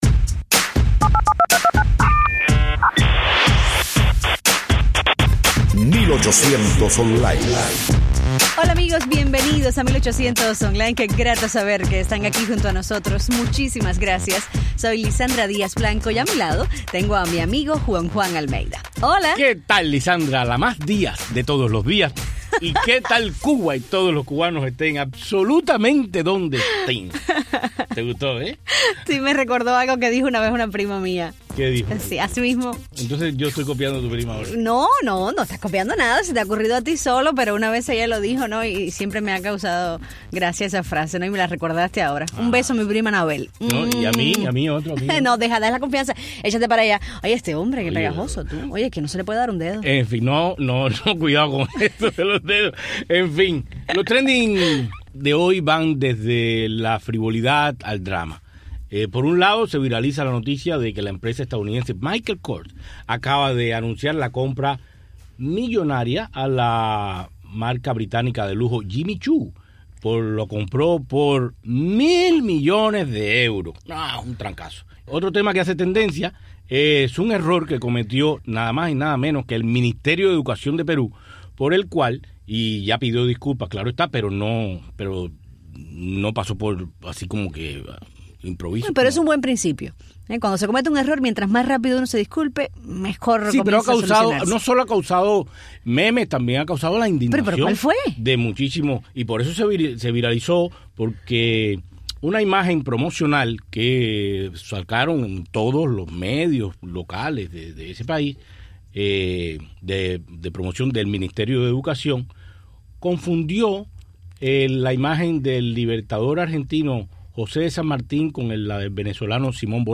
Entrevista a la cantante cubana Daymé Arocena sobre su nuevo álbum Al-Kemi